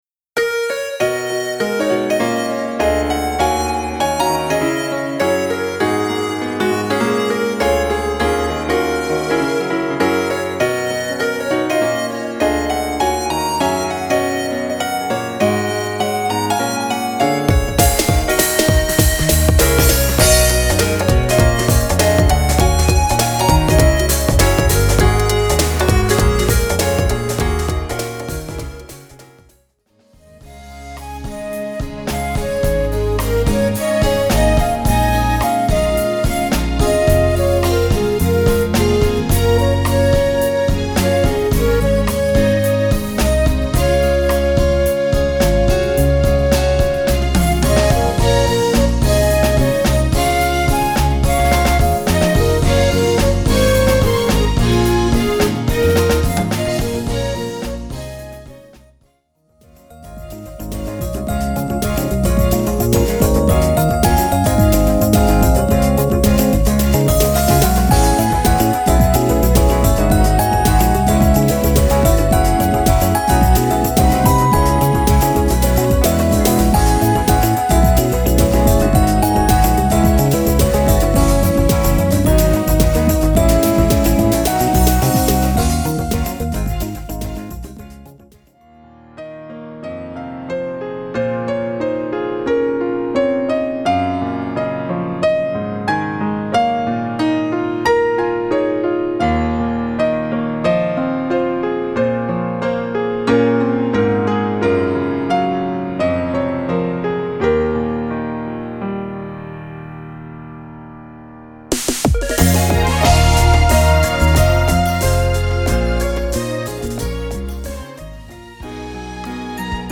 東方アレンジでもなく、ヴォーカルもなく、生演奏も(ほとんど)ありません。
ジャンル : オリジナル (Inst.)
クロスフェードデモ